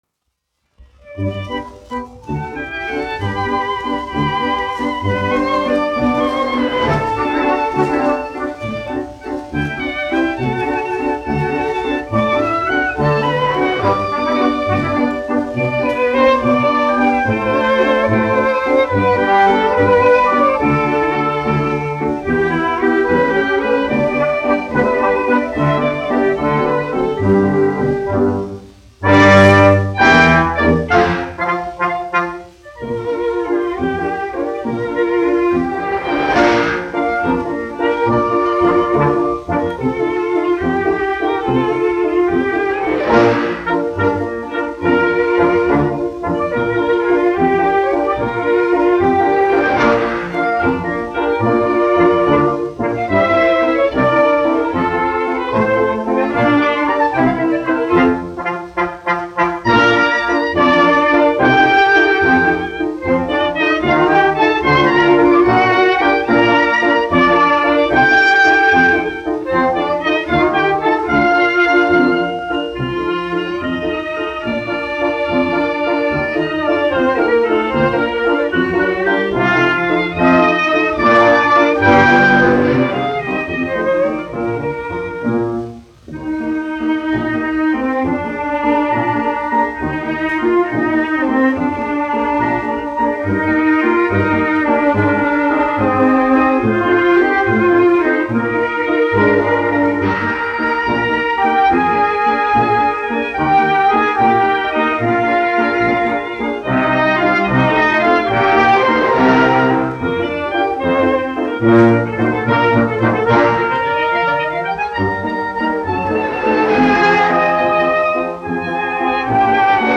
1 skpl. : analogs, 78 apgr/min, mono ; 25 cm
Valši
Operetes--Fragmenti
Skaņuplate